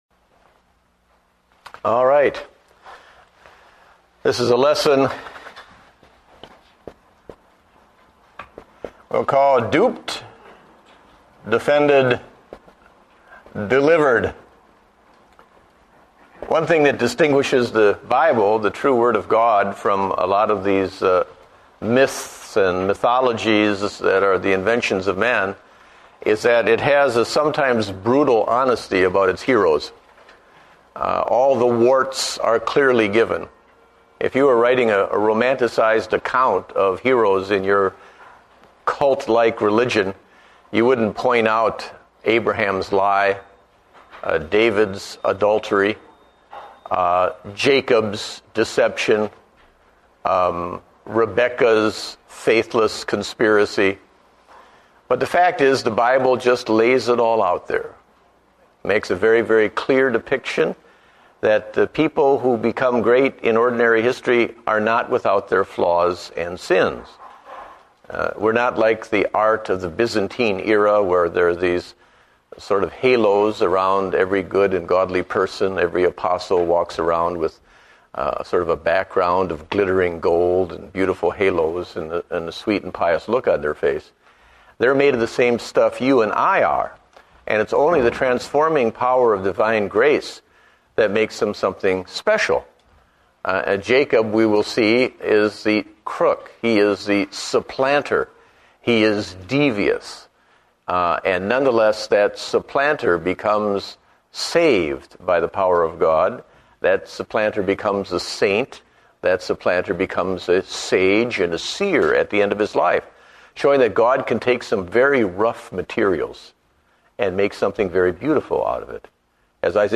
Date: March 29, 2009 (Adult Sunday School)